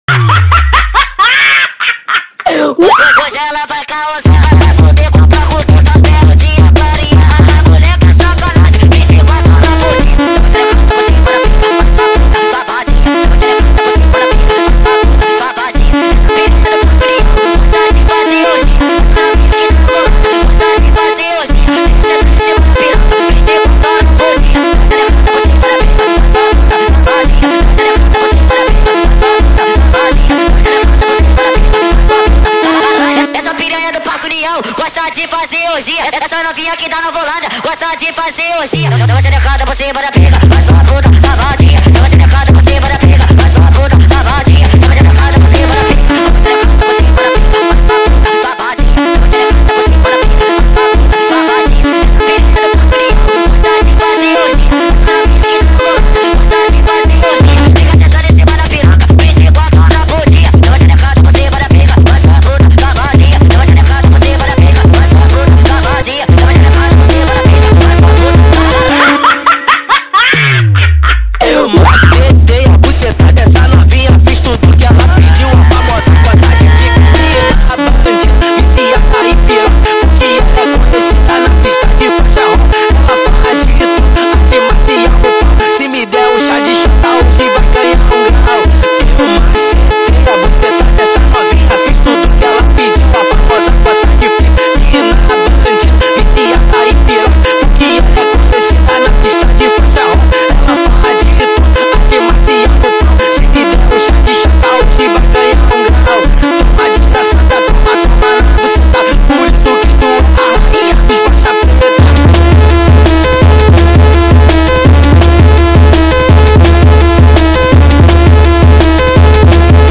با ریتمی تند و خشن در نسخه Sped Up
فانک